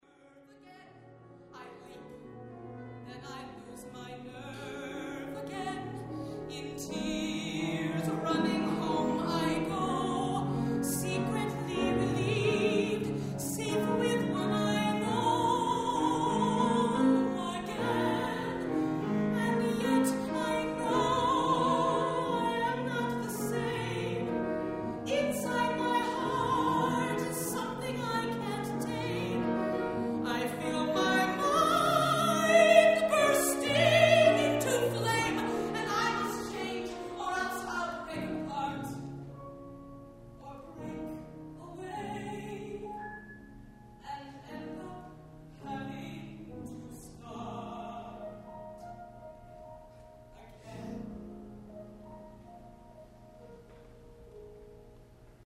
(Ballad)